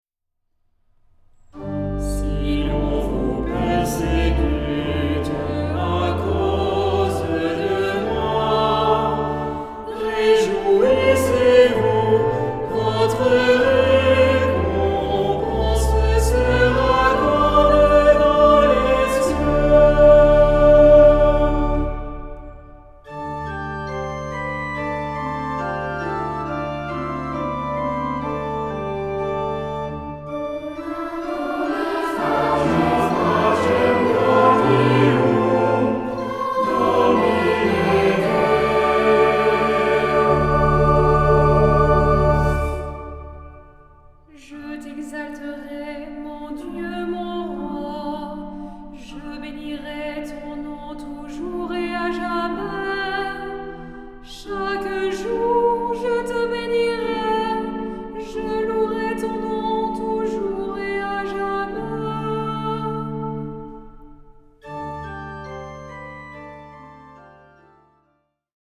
Genre-Style-Form: troparium ; Psalmody
Mood of the piece: collected
Type of Choir: SATB  (4 mixed voices )
Instruments: Organ (1)
Tonality: D tonal center